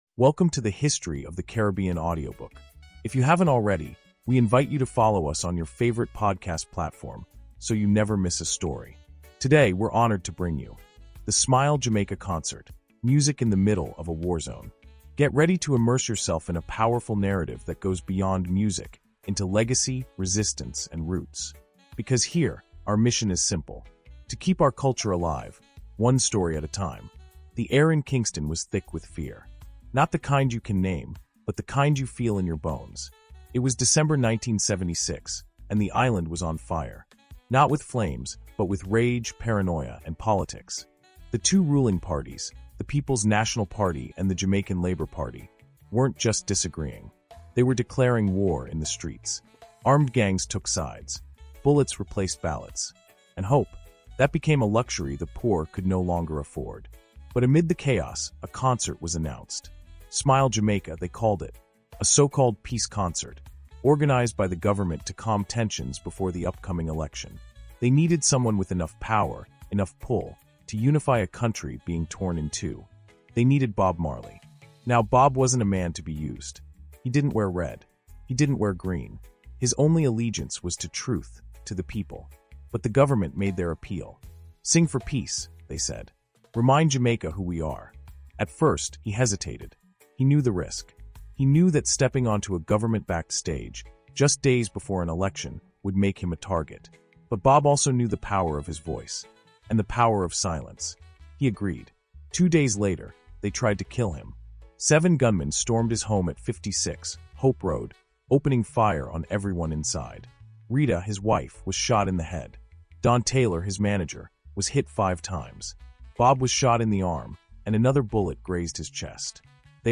“The Smile Jamaica Concert – Music in the Middle of a Warzone” is a immersive audio experience that drops listeners into one of the boldest, most courageous moments in Bob Marley’s life.
This insight tells the story behind that legendary night—how political warfare threatened to silence a man who preached peace, and how music became his act of rebellion. Listeners will feel the tension, the fear, the swelling crowd, and the raw defiance in Marley’s voice as he sang not for applause—but for survival, unity, and truth.